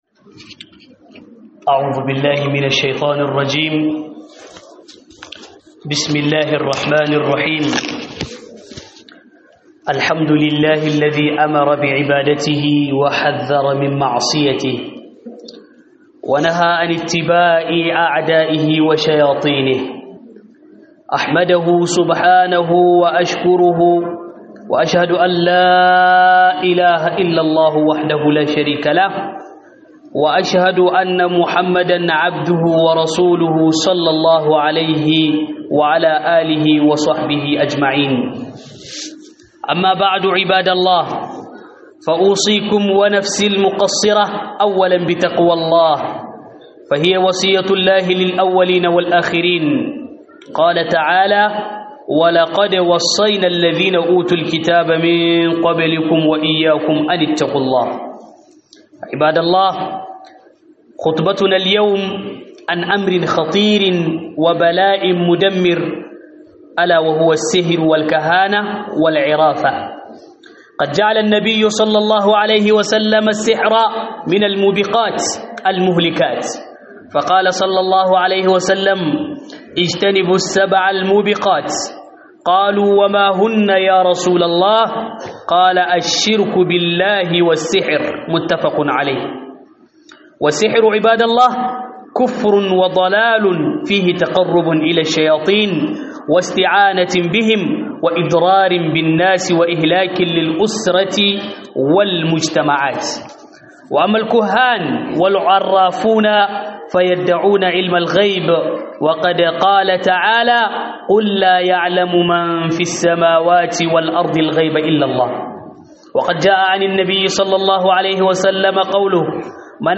Huɗubar juma'a Haɗarin sihiri